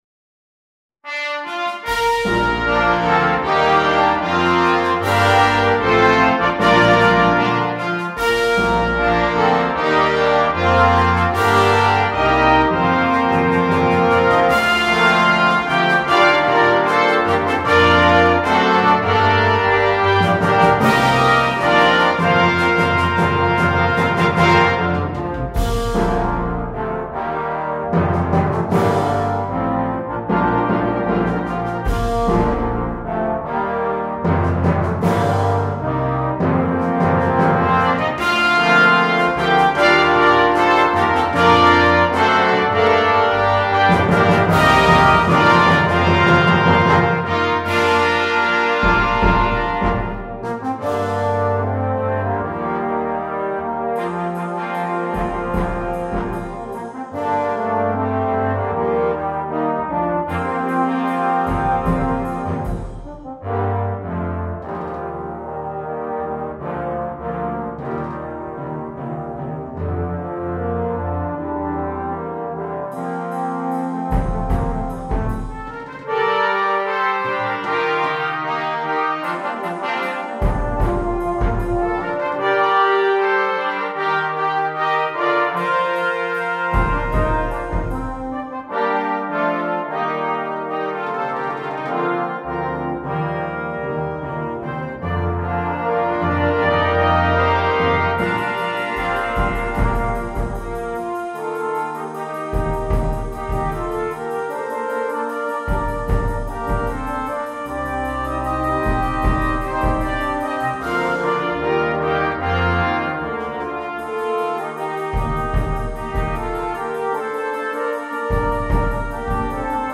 2. Brass Band
sans instrument solo
Musique légère